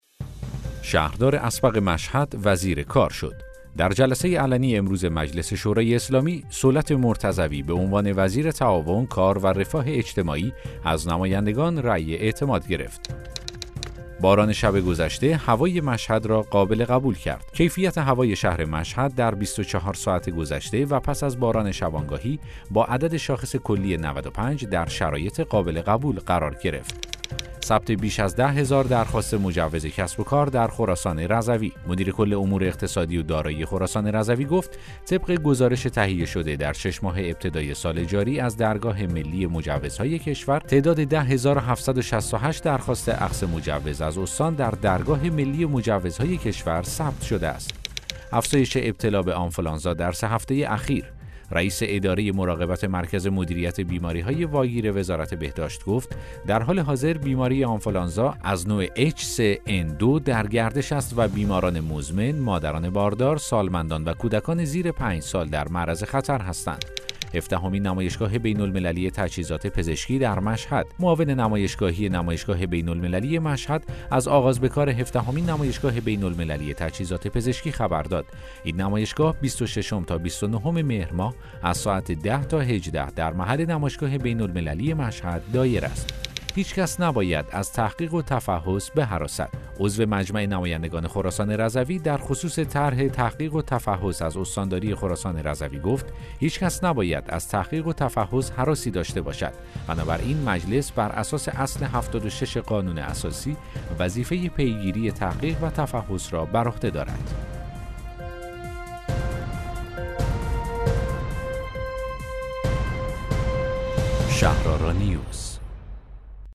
اخبار صوتی - چهارشنبه صبح ۲۷ مهر ۱۴۰۱